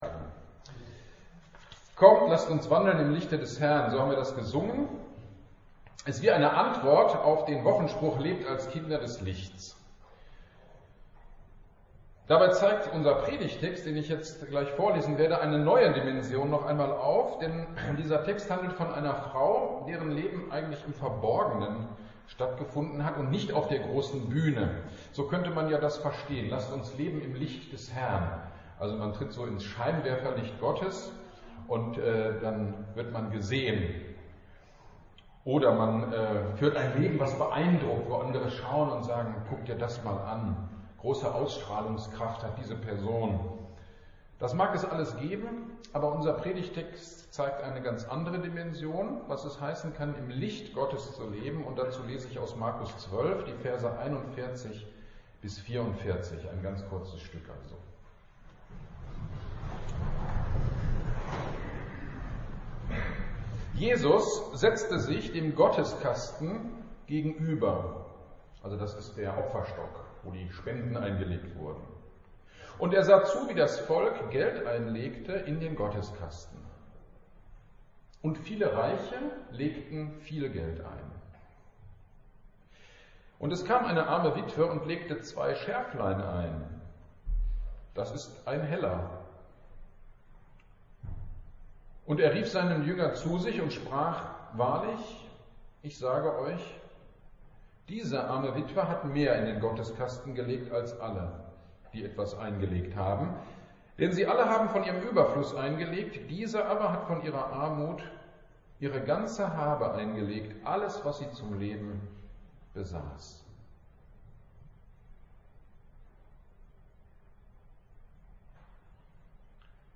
GD am 07.08.22 Predigt zu Markus 12,41-44 - Kirchgemeinde Pölzig